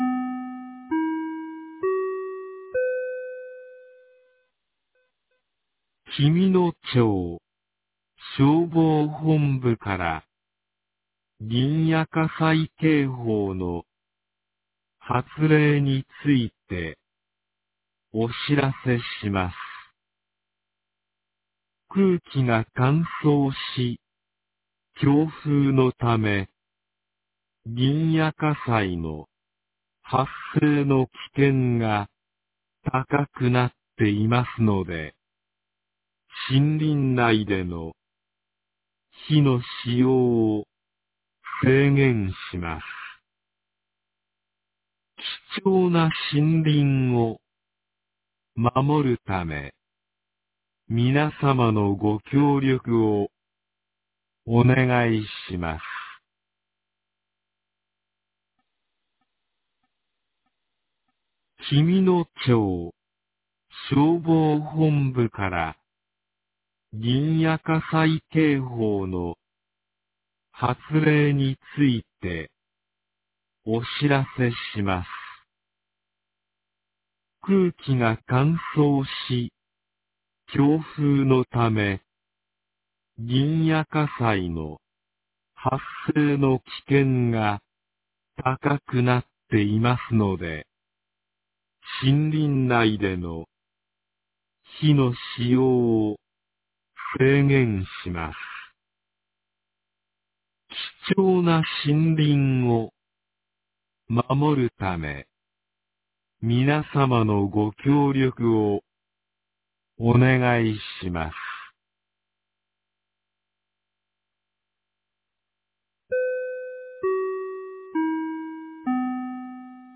2026年02月02日 16時10分に、紀美野町より全地区へ放送がありました。